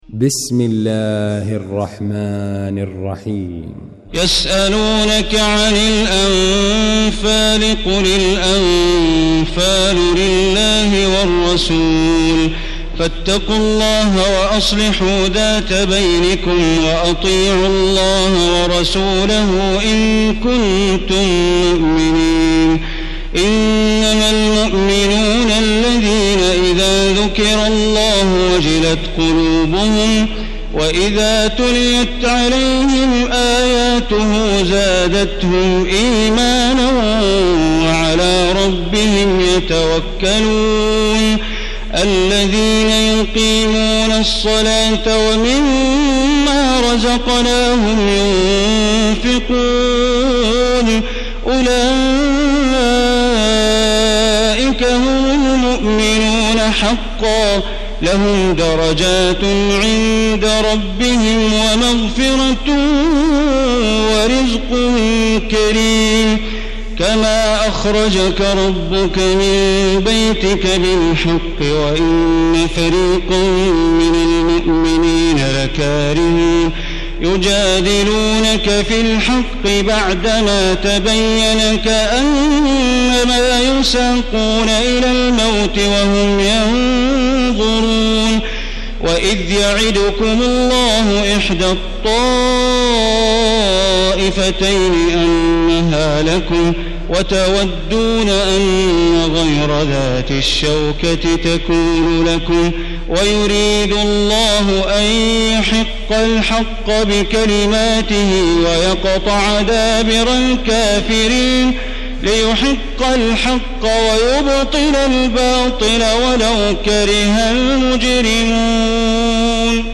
المكان: المسجد الحرام الشيخ: معالي الشيخ أ.د. بندر بليلة معالي الشيخ أ.د. بندر بليلة سعود الشريم الأنفال